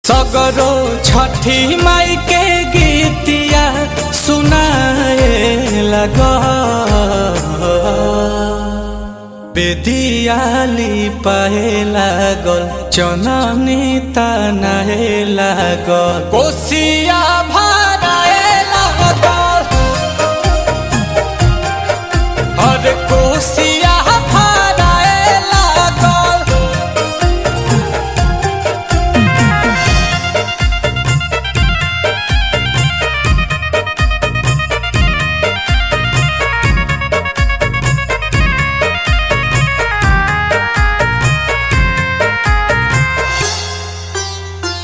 Bhojpuri Chhath Puja Ringtones